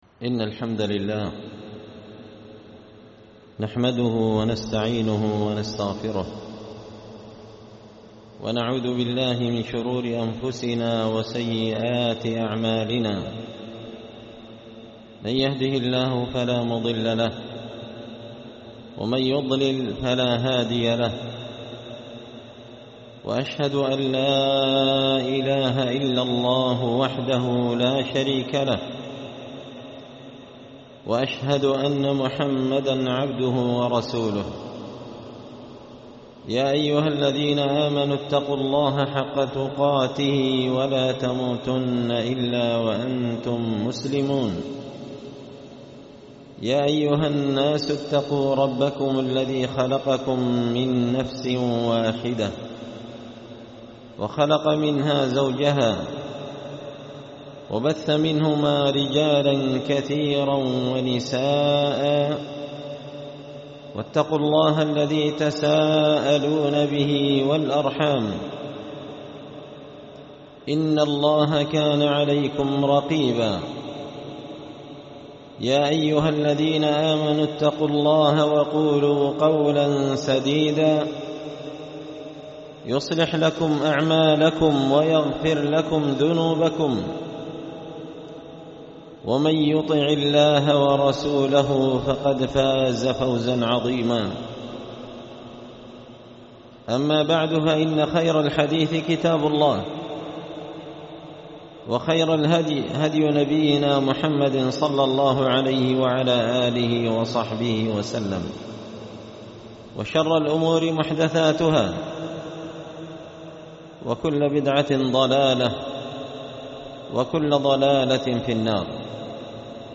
خطبة جمعة بعنوان:
ألقيت هذه الخطبة بمنطقة جدوه حصوين-المهرة-اليمن